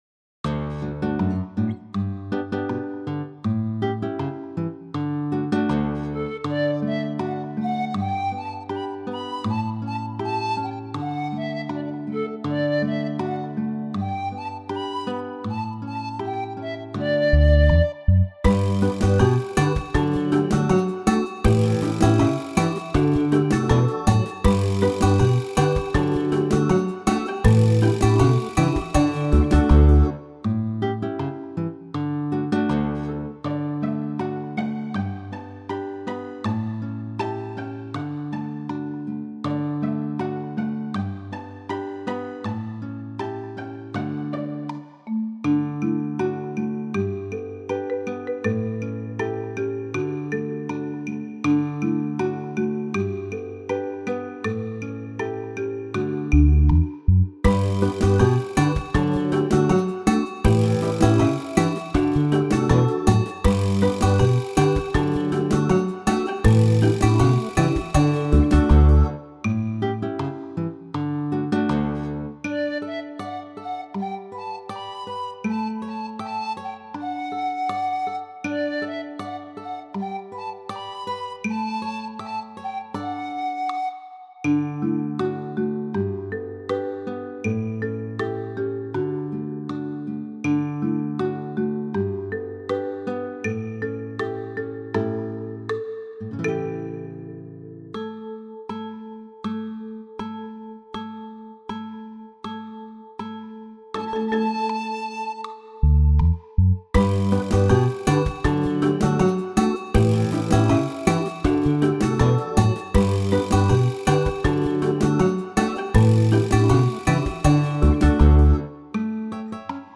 A fun and catchy song about learning to tell the time.
backing track